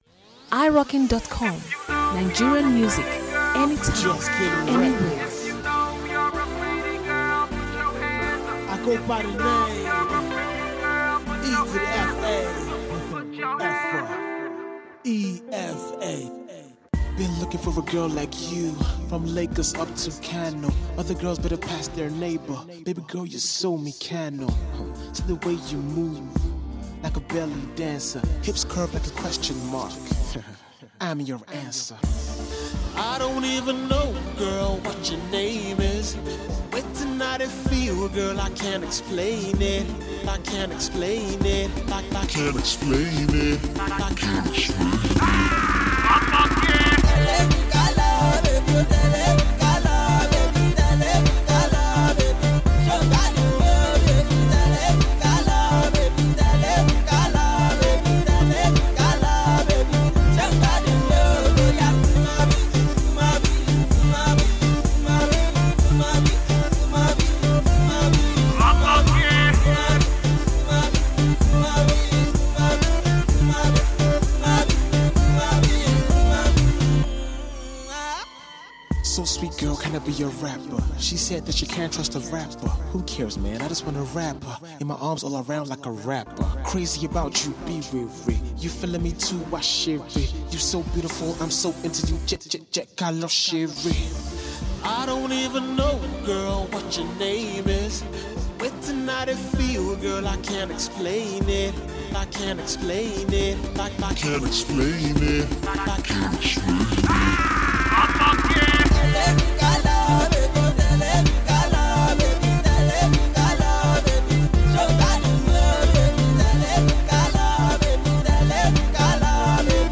club dance track